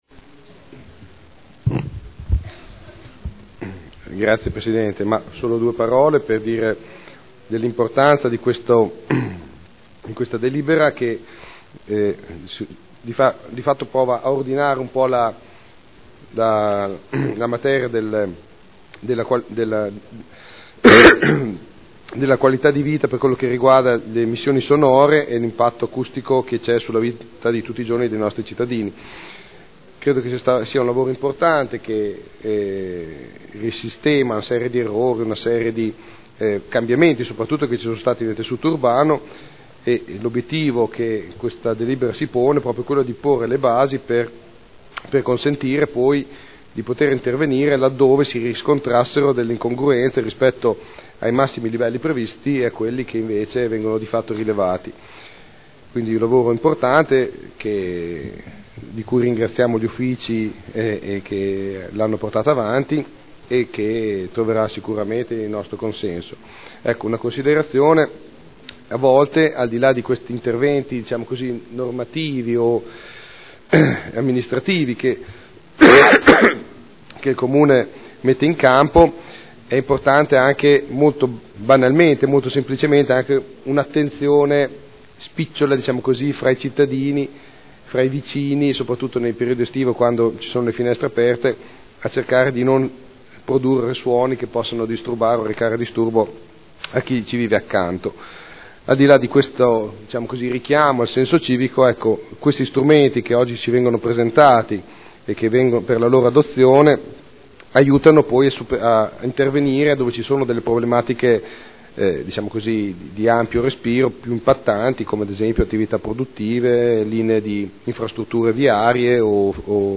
Enrico Artioli — Sito Audio Consiglio Comunale
Seduta del 24 febbraio. Proposta di deliberazione: Aggiornamento della classificazione acustica del territorio comunale – Adozione. Dibattito